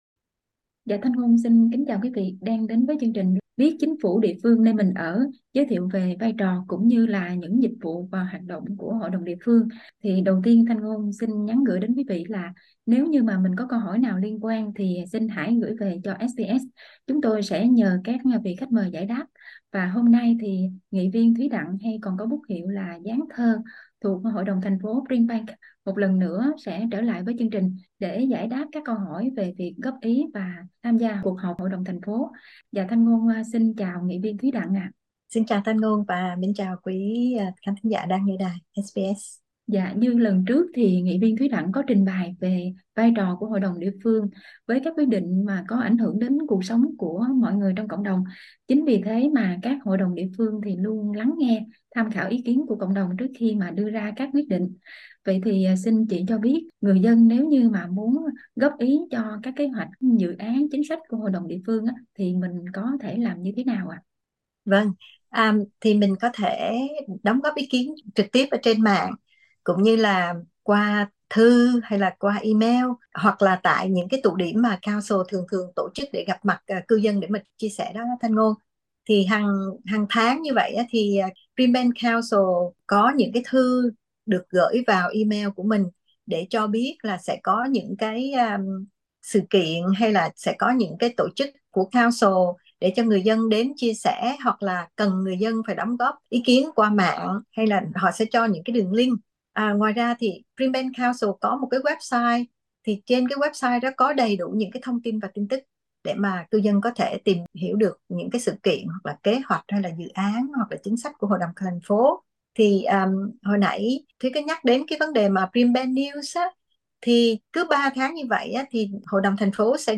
Các ý kiến, đề xuất của người dân gửi đến Hội đồng địa phương được xem xét như thế nào? SBS Vietnamese tìm hiểu qua cuộc trò chuyện với nghị viên Thuý Đặng đến từ hội đồng thành phố Brimbank.